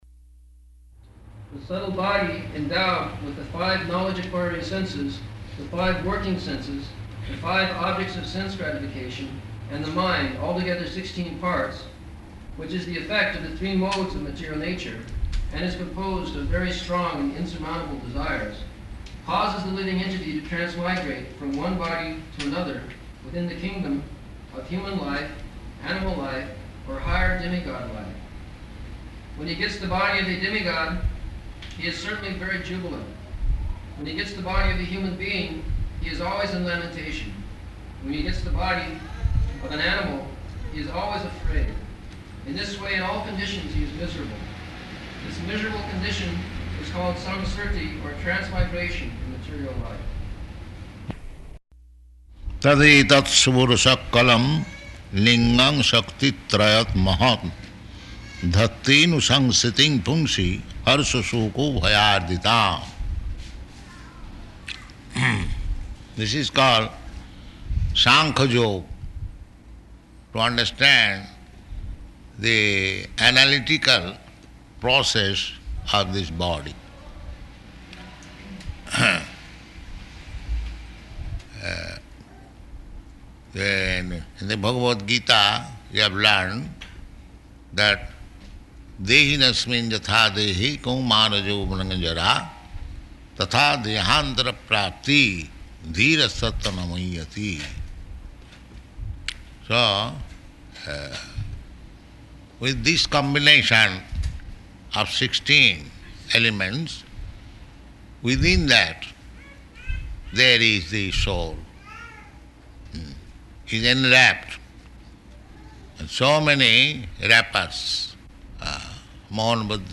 Location: Detroit